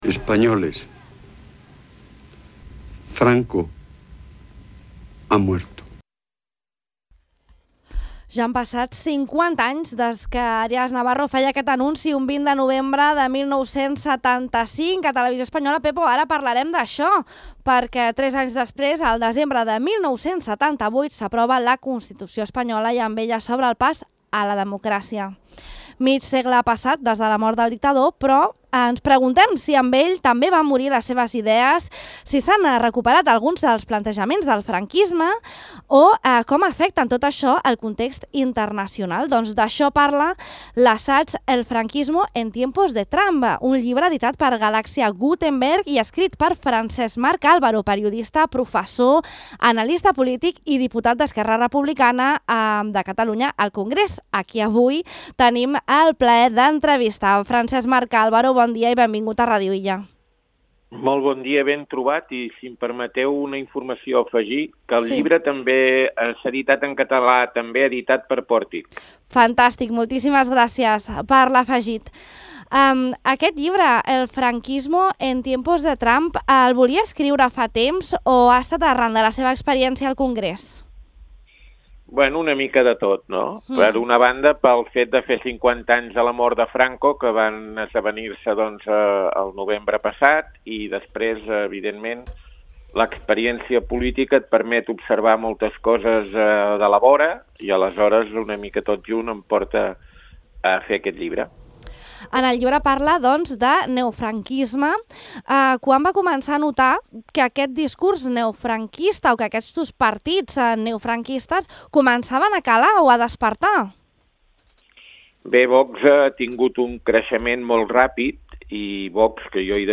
El periodista, professor i diputat d’ERC al Congrés Francesc-Marc Álvaro ha reflexionat a Ràdio Illa sobre la persistència d’algunes inèrcies del franquisme en la política actual arran del seu llibre 'El franquisme en temps de Trump', editat per Pòrtic en català i per Galaxia Gutemberg en csatellà.